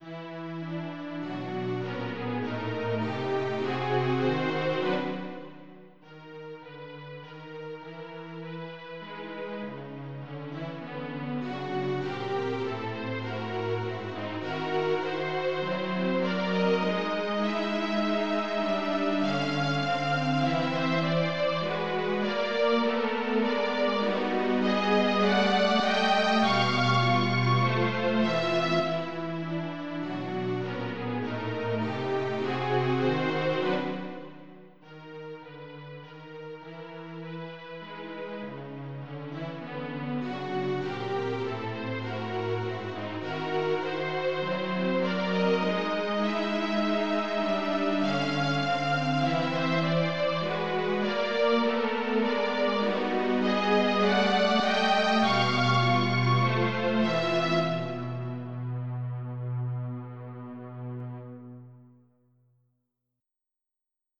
Aber dieses Machwerk klingt eher so, als hätte man die Katze im Trockner vergessen.
Es könnte der Soundtrack zu einem dänischen Problemfilm sein.
Hilft genau nichts, um das Rätsel zu lösen, und musikalischen Wohlklang könnt ihr euch leider auch nicht erwarten.
Das Ganze leider nur als computergenerierten Sound, ich wollte meine Streicherkollegen nicht mit sowas belästigen, um eine echte Aufnahme hinzukriegen.